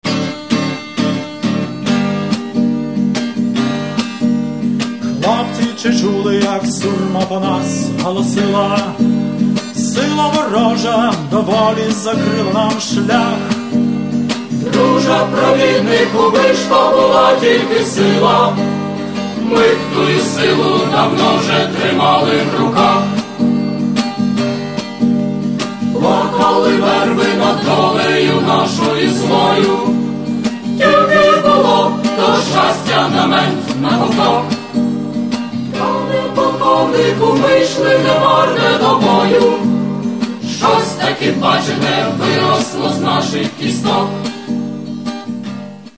Каталог -> Естрада -> Збірки